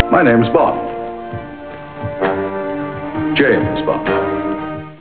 Hear Moore's introduction!